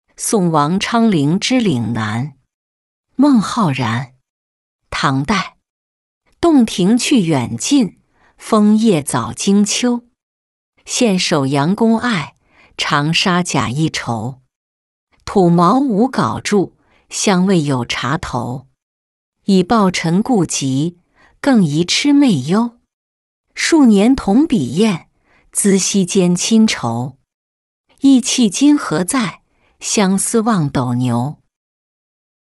送王昌龄之岭南-音频朗读